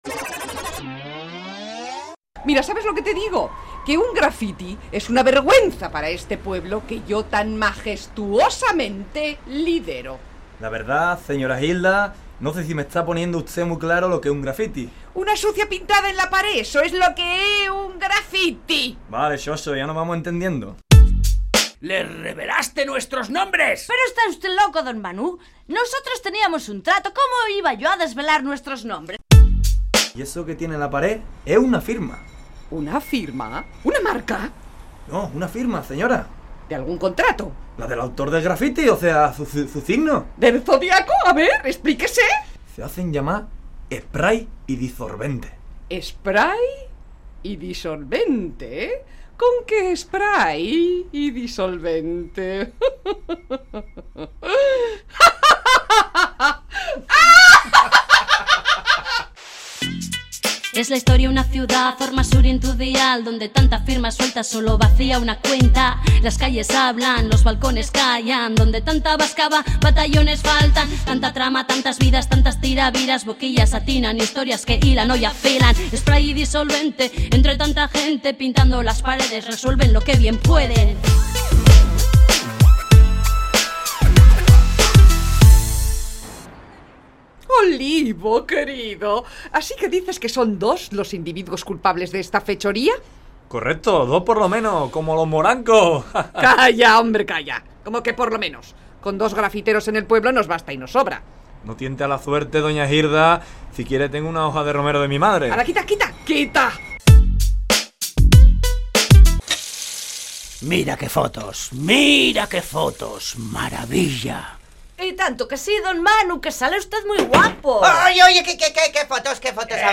Entrega número 23 de la Radio-Ficción “Spray & Disolvente”